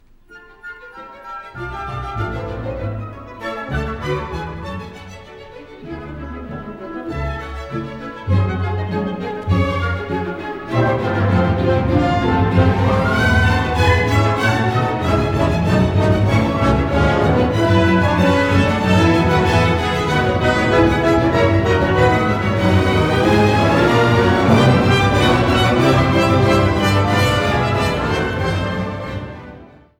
repräsentative Live-Aufnahmen
Thema: Andante grazioso